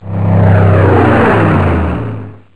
snd_27641_flyby.wav